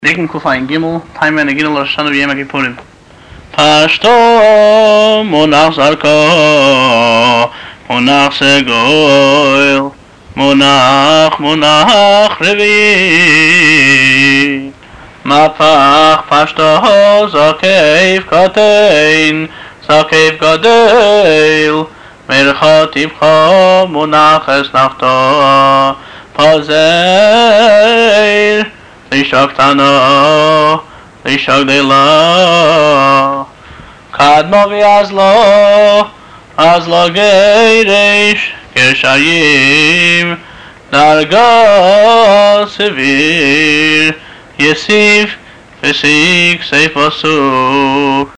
טעמי הנגינה - נוסח חב"ד